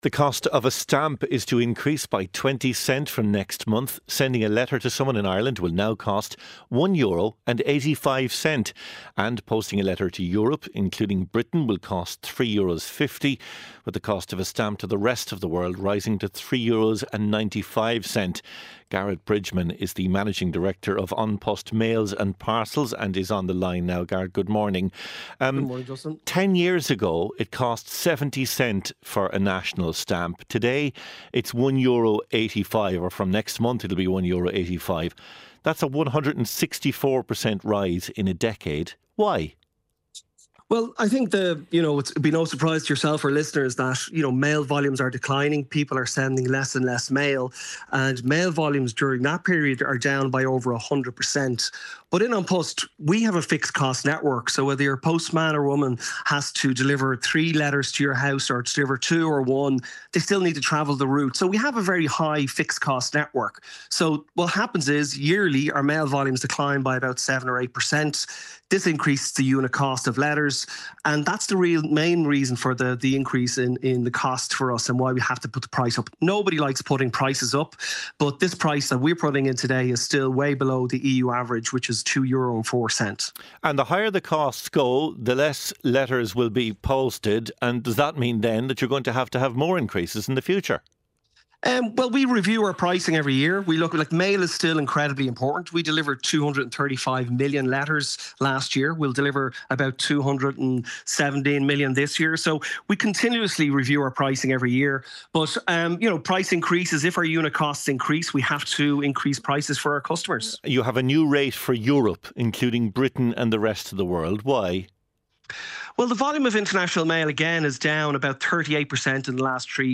Без регистрации или установки. 8am News Bulletin. Weather Forecast.